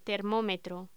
Locución: Termómetro
voz